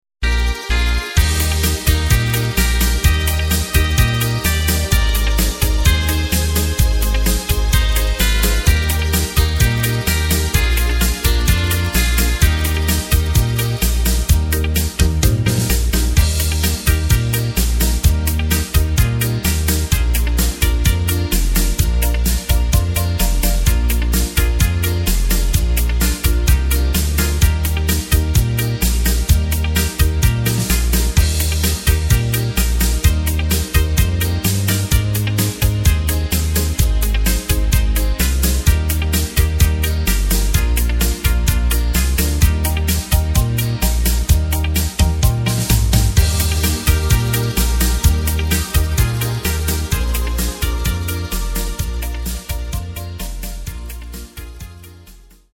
Takt:          4/4
Tempo:         128.00
Tonart:            Eb
Schlager aus dem Jahr 1992!
Playback mp3 Mit Drums